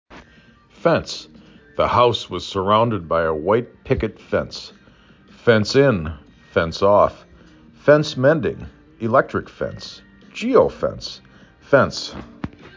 5 Letters, 1 Syllable
f e n s